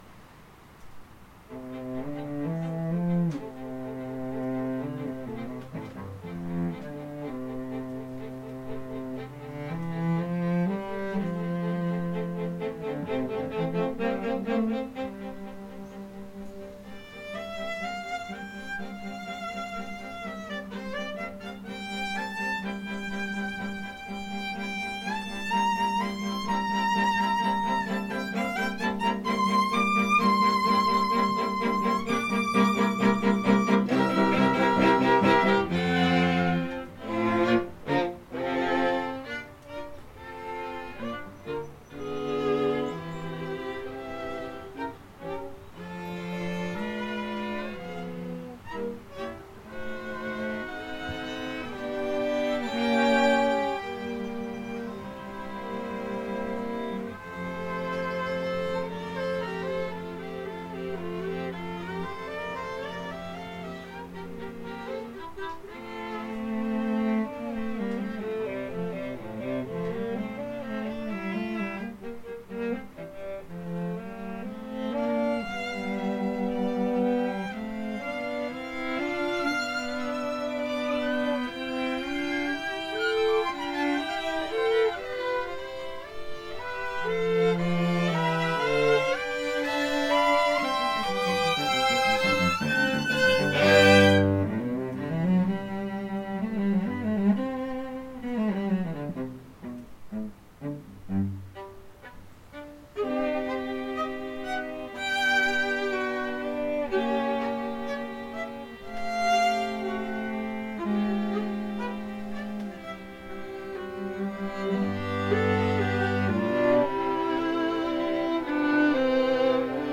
the students
Chamber Groups